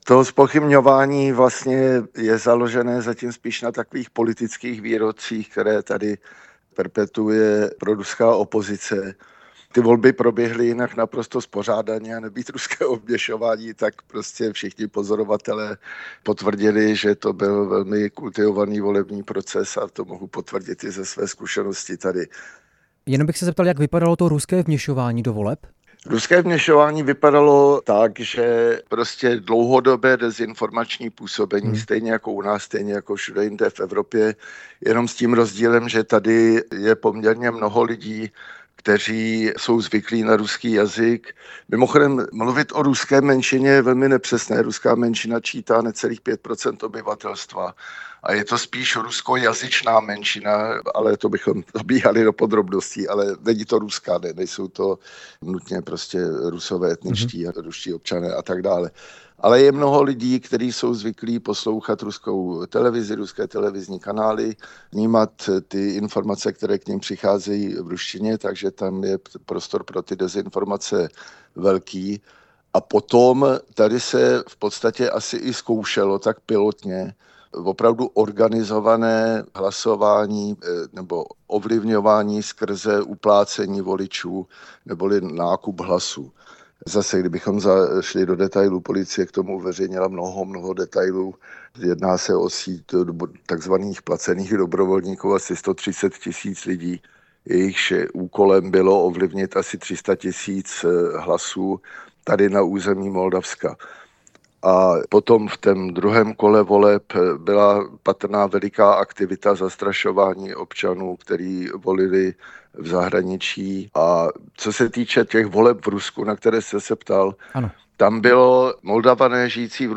Zabírá na Moldavany zpochybňování volebních výsledků ze strany Kremlu? Nejen na to odpovídal host Radia Prostor Jaromír Plíšek, velvyslanec České republiky v Moldavsku.
Rozhovor s Jaromírem Plíškem